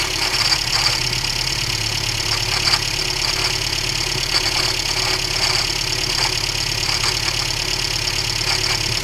V převodovce vozu Felicia 1.3 bmm z roku 1996 je slyšet nápadné kovové drnčení.
Druhý přiložený audio soubor obsahuje záznam zvuku pořízený speciálním stetoskopem na auta přiloženým přímo na víko převodovky.
prevodovka-stetoskop.mp3